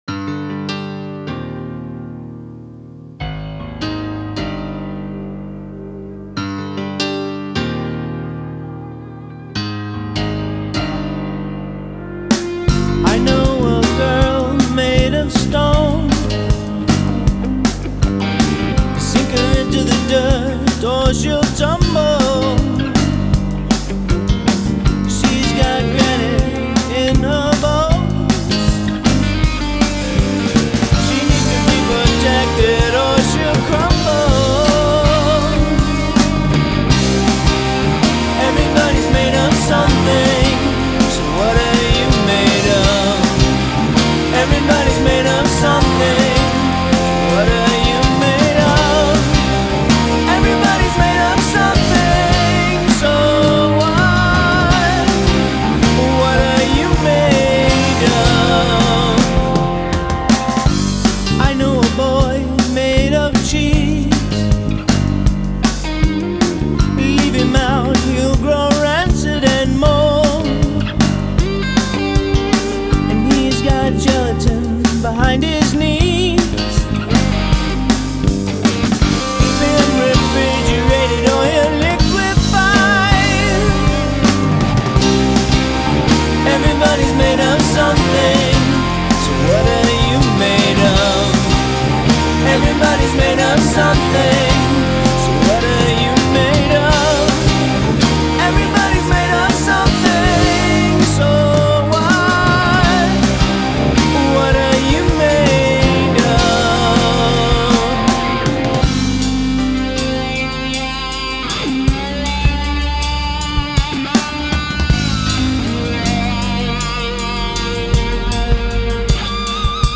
bass
guitar